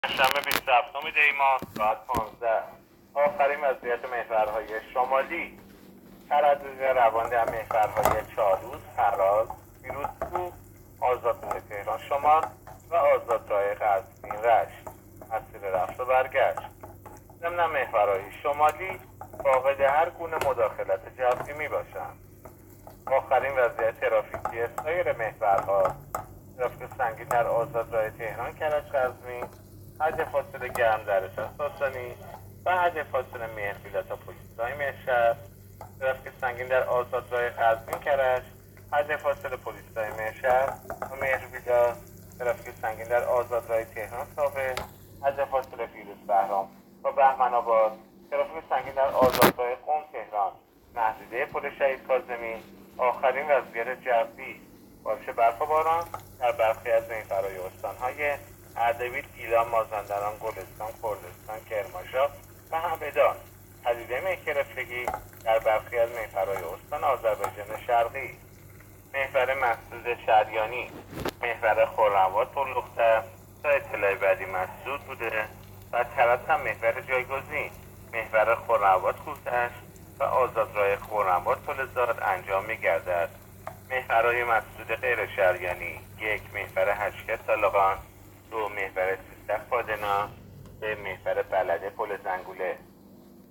گزارش رادیو اینترنتی از آخرین وضعیت ترافیکی جاده‌ها تا ساعت ۱۵ بیست و هفتم دی؛